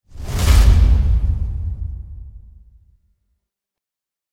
Scary Sounds / Sound Effects 31 Jan, 2026 Cinematic Fast Rise Whoosh & Hit Sound Effect Read more & Download...
Cinematic-fast-rise-whoosh-hit-sound-effect.mp3